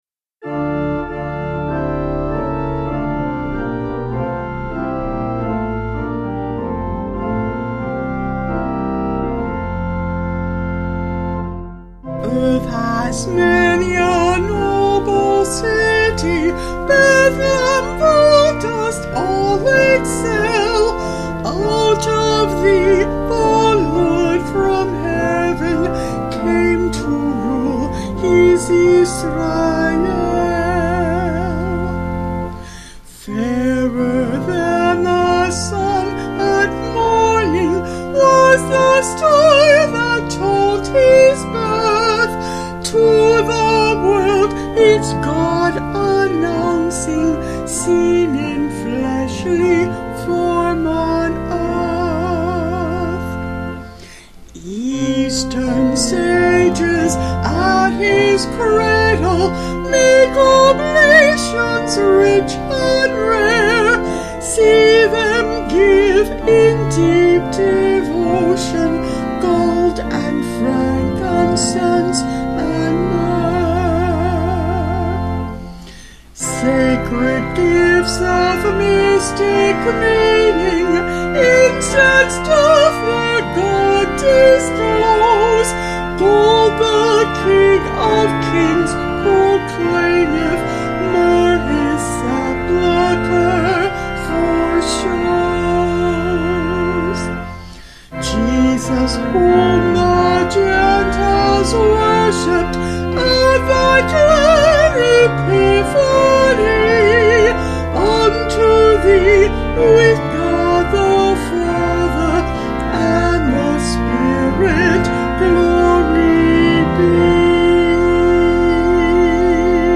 Vocals and Organ
250kb Sung Lyrics